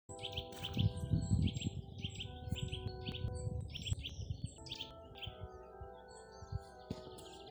Nuthatch, Sitta europaea
Administratīvā teritorijaLīgatnes novads
StatusSinging male in breeding season